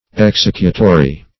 Executory \Ex*ec"u*to*ry\, a. [LL. executorius, L. exsecutorius: